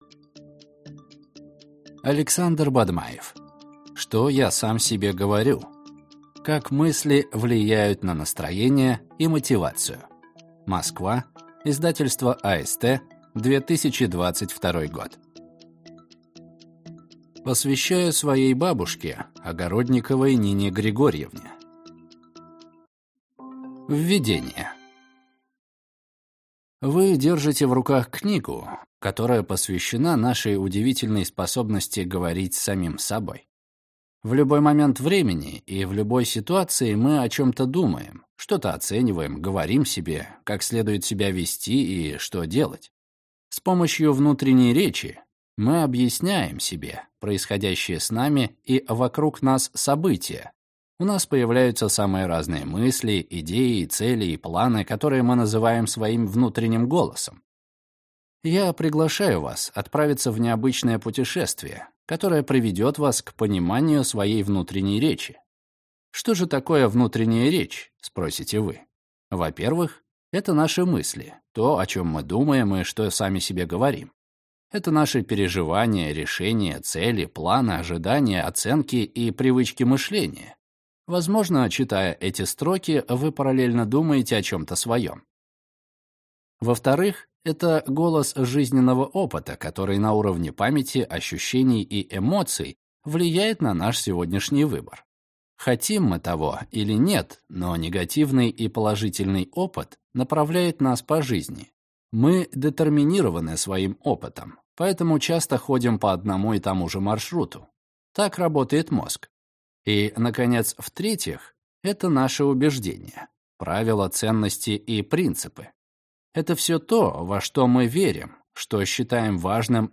Аудиокнига Что я сам себе говорю. Как мысли влияют на настроение и мотивацию | Библиотека аудиокниг